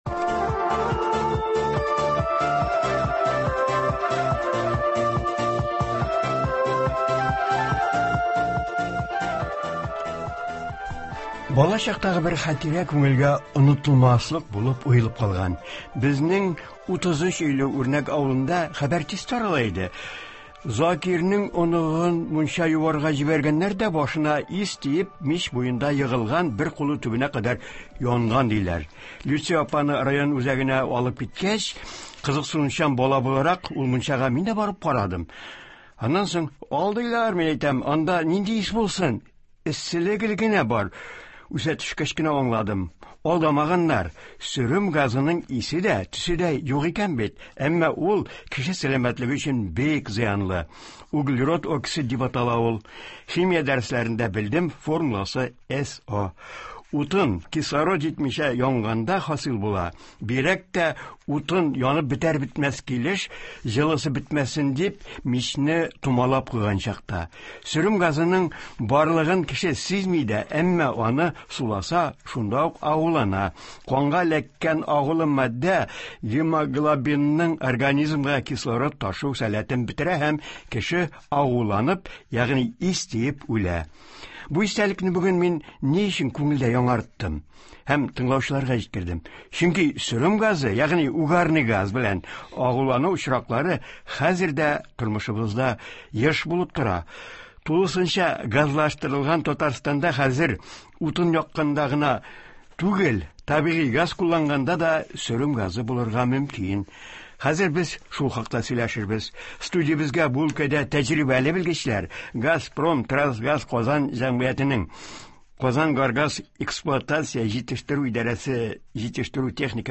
Көннәр салкынайтып, җылыту сезоны башлангач, сөрем газы (угарный газ) белән агулану очраклары ешая. Мондый фаҗигалар булмасын өчен нинди кагыйдәләрне үтәргә, кышкы чорга ничек әзерләнергә, агулану очраклары булганда кемгә мөрәҗәгать итәргә? Болар хакында турыдан-туры эфирда
киңәшләр һәм тыңлаучылар сорауларына җаваплар бирәчәк.